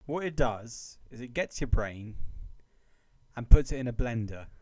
Play Brain in a blender, Download and Share now on SoundBoardGuy!
blender.mp2_.mp3